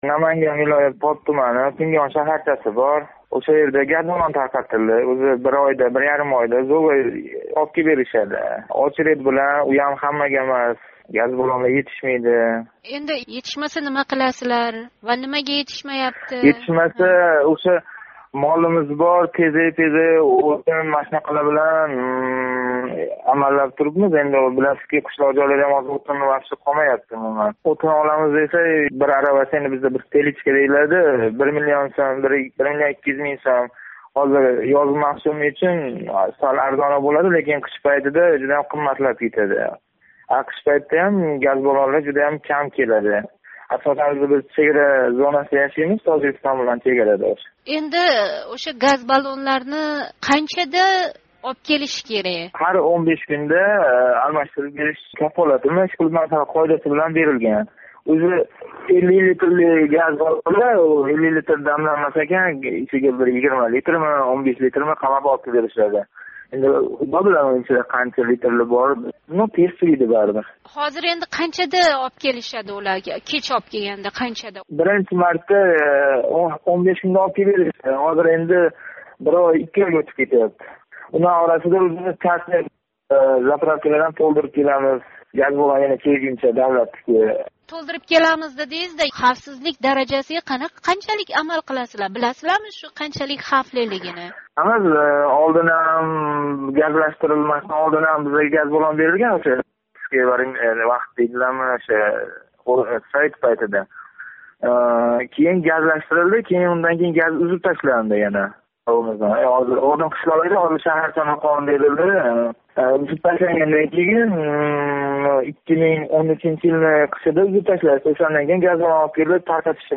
Газ баллонлари кечикаётганидан шикоят қилган поплик йигит билан суҳбат